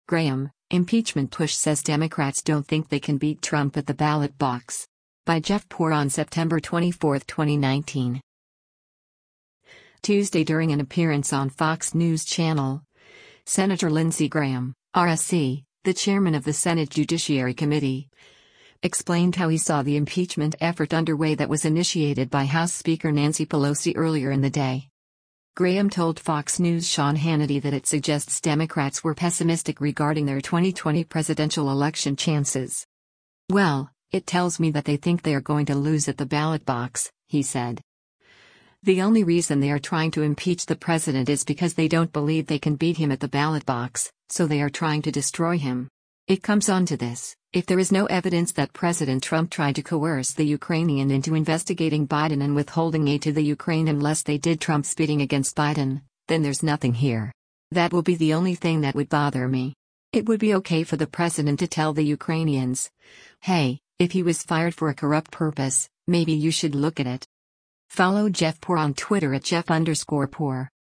Tuesday during an appearance on Fox News Channel, Sen. Lindsey Graham (R-SC), the chairman of the Senate Judiciary Committee, explained how he saw the impeachment effort underway that was initiated by House Speaker Nancy Pelosi earlier in the day.
Graham told Fox News’ Sean Hannity that it suggests Democrats were pessimistic regarding their 2020 presidential election chances.